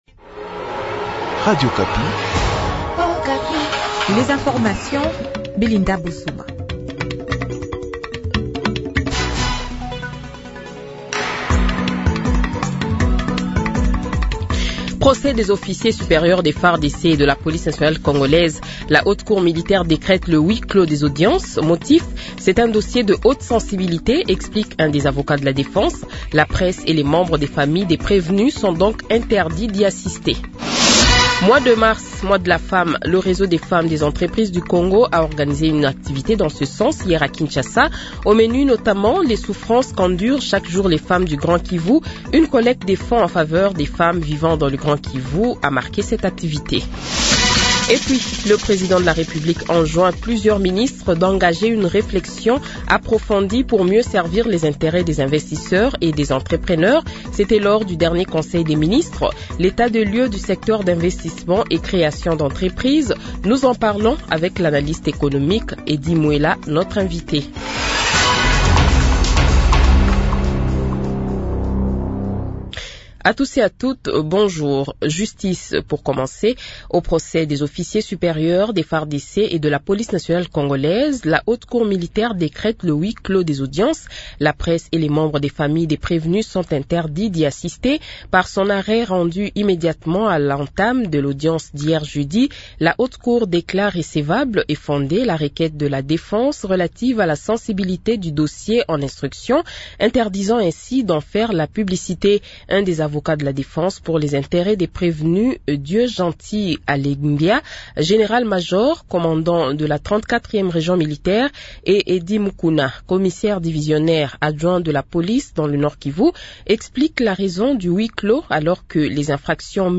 Le Journal de 8h, 21 Mars 2025 :